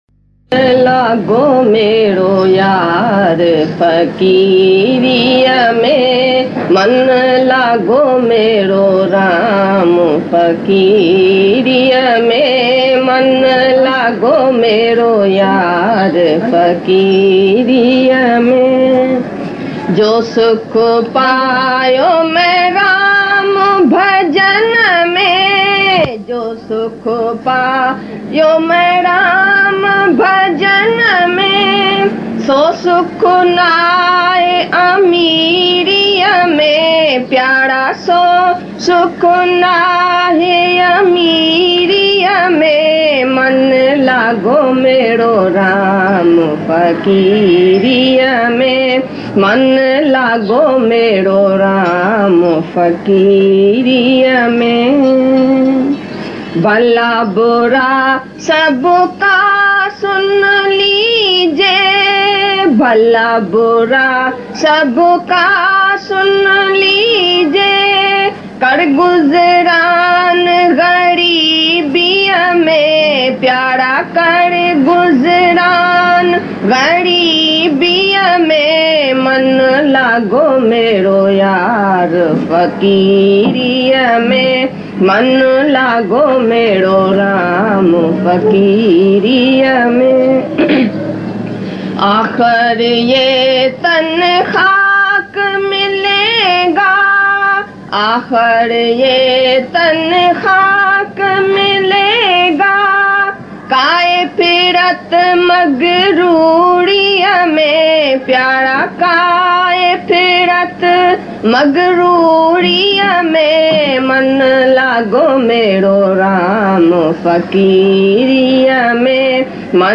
Mann-Laago-Mero-Raam-Fakiriya-Mein-Bhajan.mp3